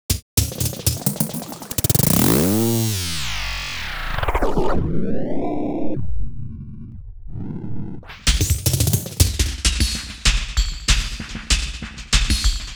98WAGONFX2-L.wav